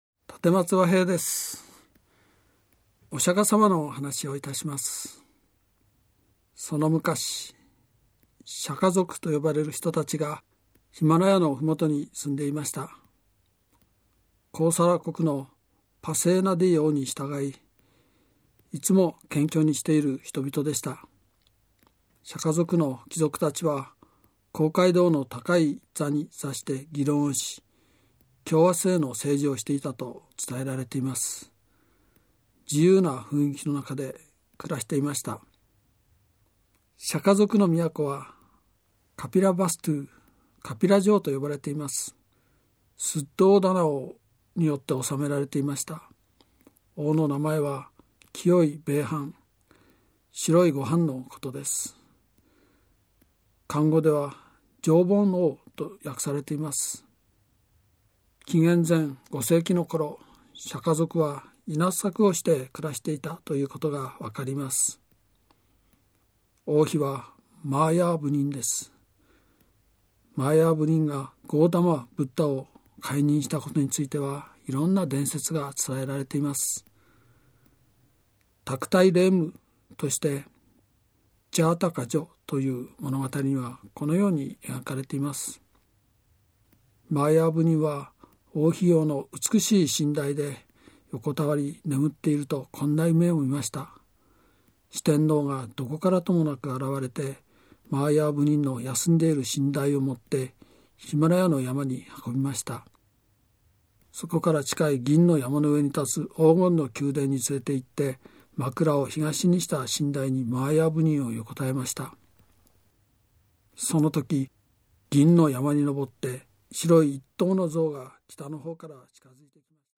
[オーディオブック] 聴く歴史・海外『無常の彼方へ 永遠の真理を探究したブッダの旅』